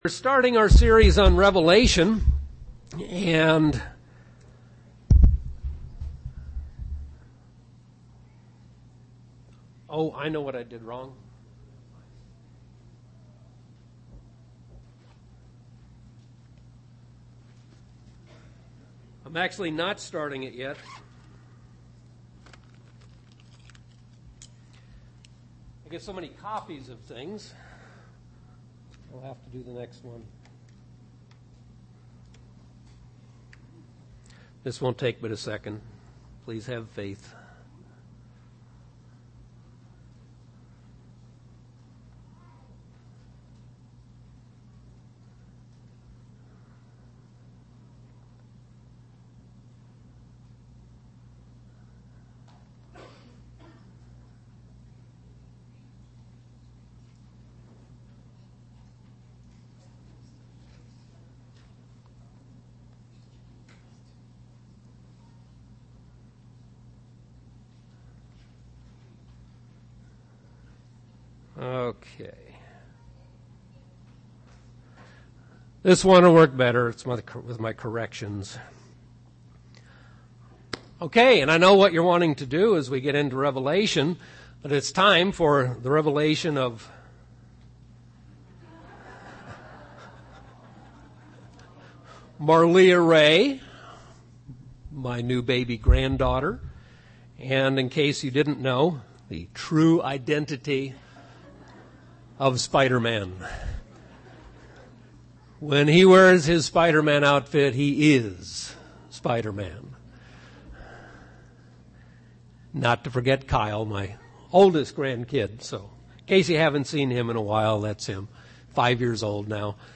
Given in Phoenix East, AZ
Print Introduction to the Book of Revelation UCG Sermon Studying the bible?